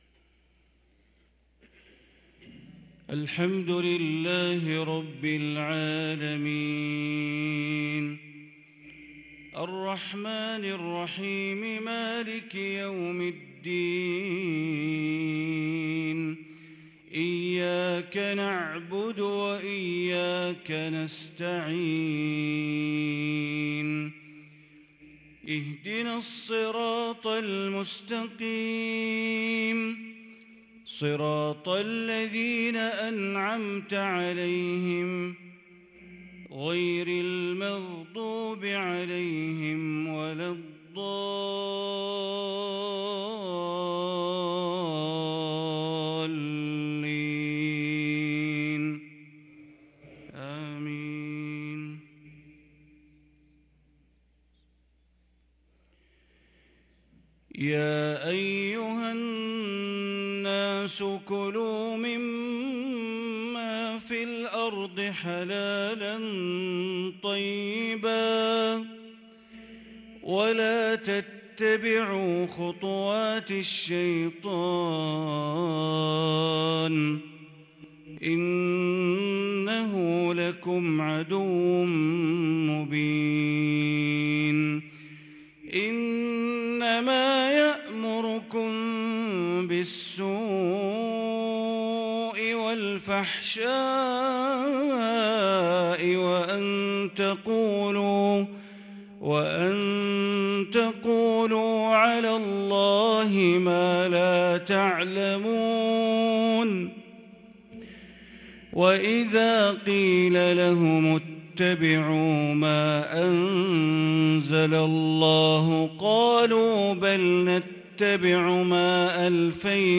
صلاة المغرب للقارئ بندر بليلة 14 جمادي الآخر 1443 هـ
تِلَاوَات الْحَرَمَيْن .